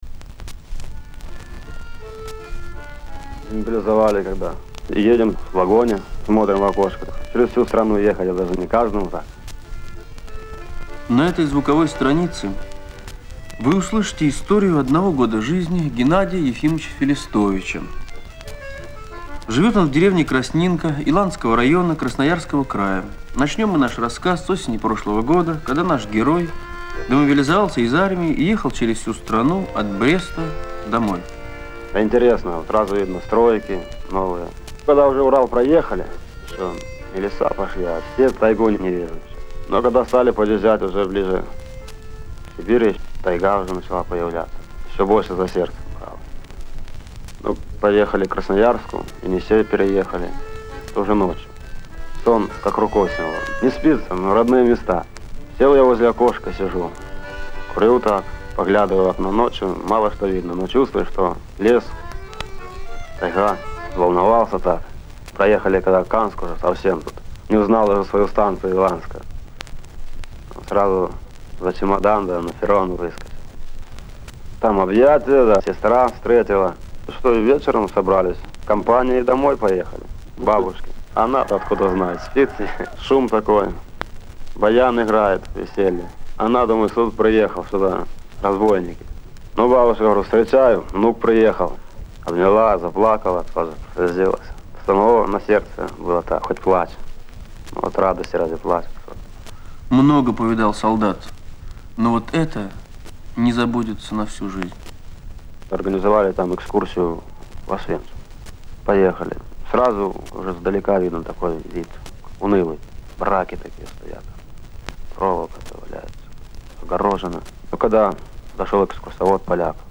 колхозник из Сибири; 'Моё дело - хлеб'.